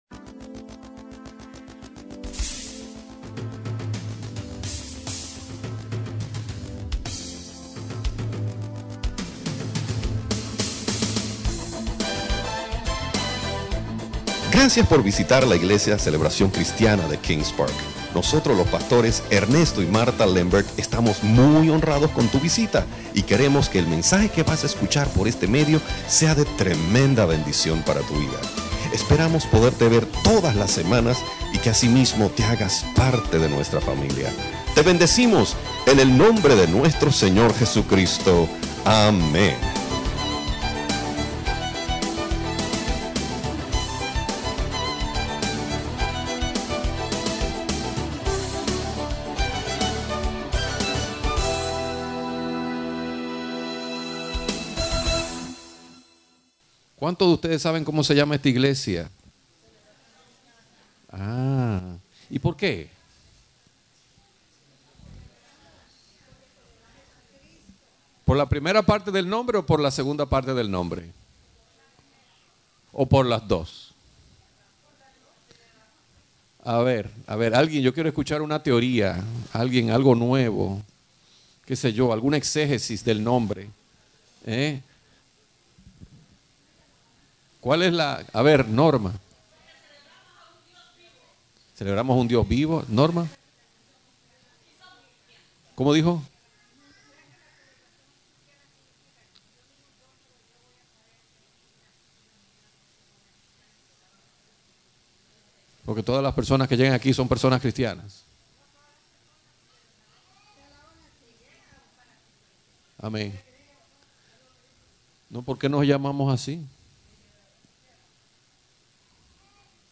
Estudio Bíblico